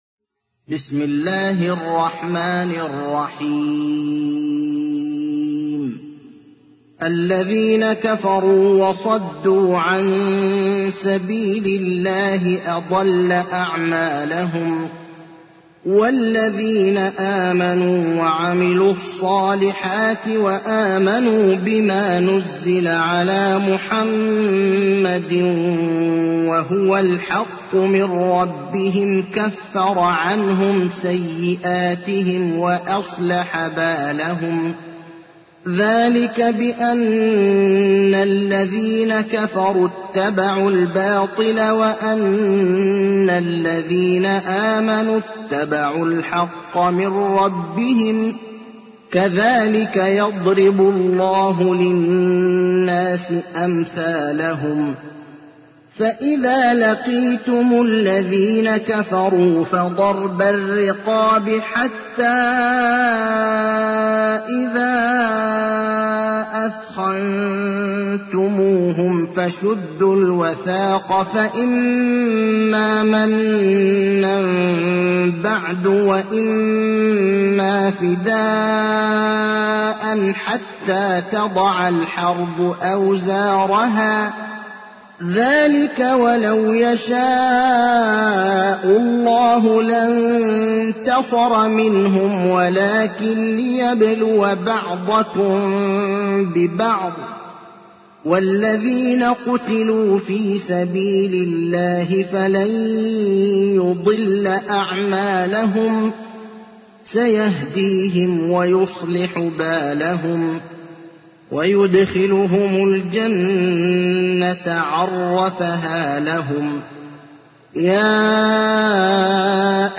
تحميل : 47. سورة محمد / القارئ ابراهيم الأخضر / القرآن الكريم / موقع يا حسين